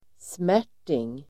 Ladda ner uttalet
smärting substantiv, canvas Uttal: [²sm'är_t:ing] Böjningar: smärtingen Definition: ett slags grovt bomullstyg canvas substantiv, kanfas , smärting , linne , grovt linne , brandsegel Förklaring: ett slags grovt bomullstyg